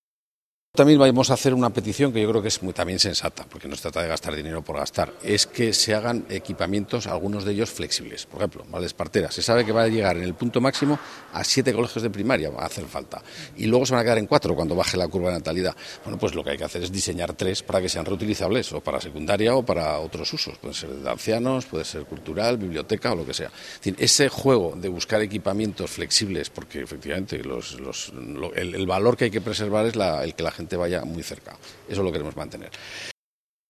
Según el Consejo, esa planificación a más largo plazo debería dotarse, además, de equipamientos flexibles que tengan en cuenta la evolución de la población y, consecuentemente, de las necesidades educativas que se van generando. Así, lo explica Jerónimo Blasco: